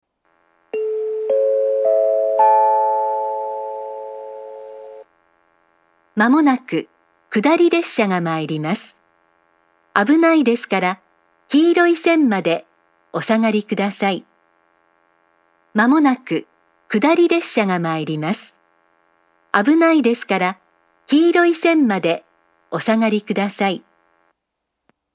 ３番線下り接近放送 １番線と同じです。１番線のスピーカーからしか流れません。